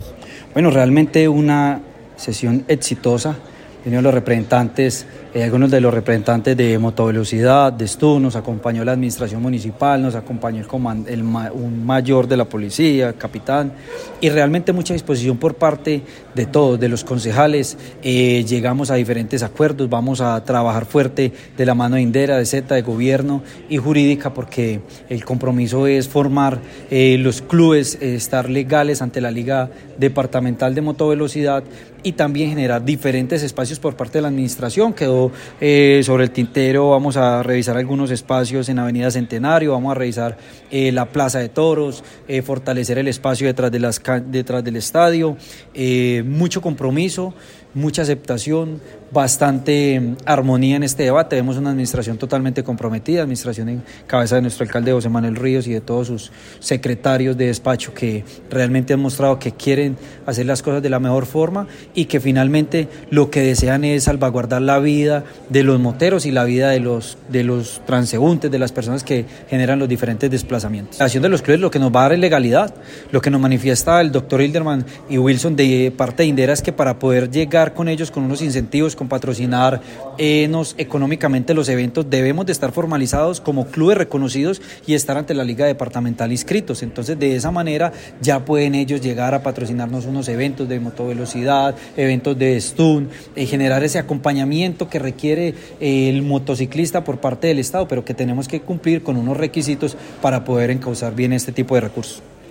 La Administración Municipal acompañó la sesión que se llevó a cabo en el Concejo Municipal, mediante proposición realizada por el concejal Jorge Mario Marín Buitrago, en la que se abordó el tema de los piques ilegales.
Audio: Concejal Jorge Mario Marín Buitrago:
Audio_Concejal_Jorge_Mario_Marin_Buitrago.mp3